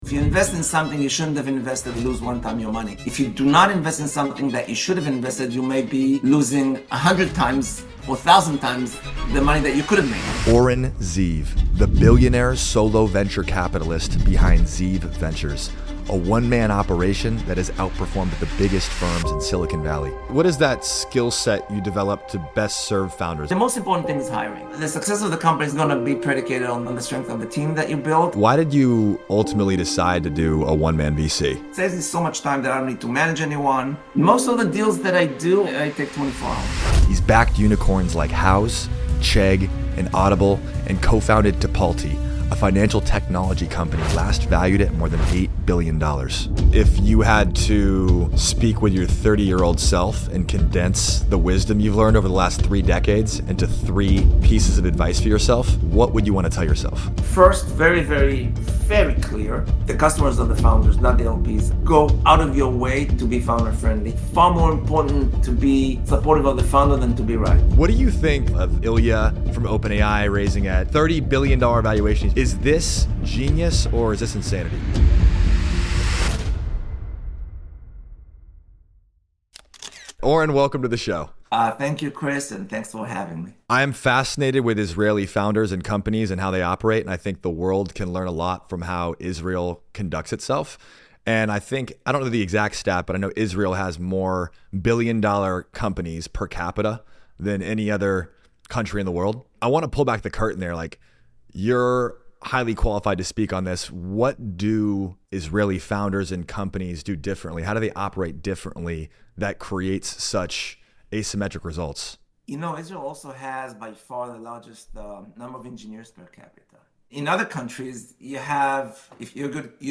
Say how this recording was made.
webinar replay